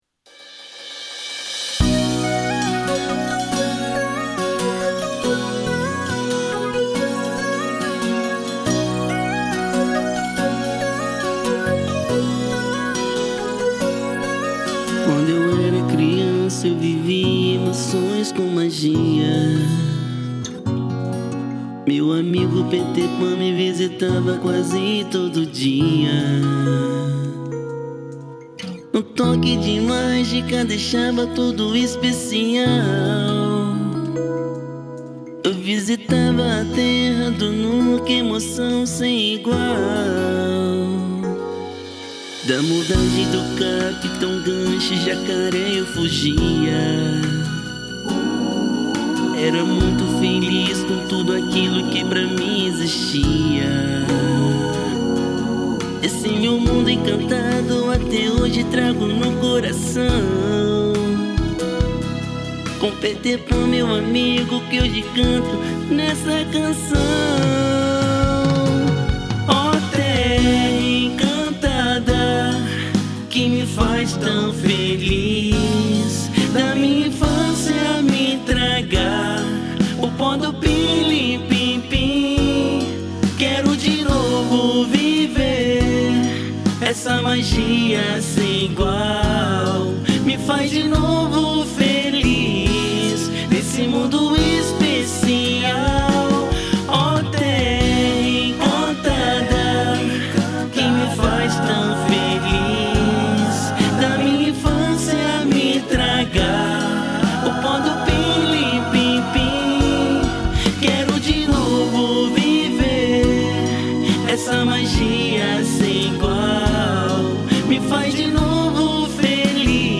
EstiloInfantil